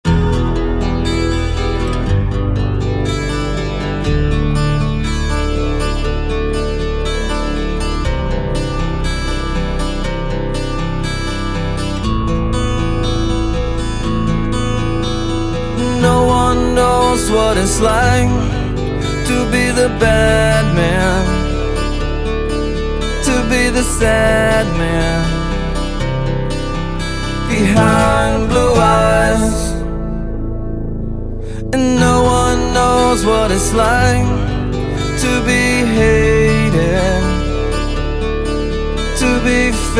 рок , баллады , гитара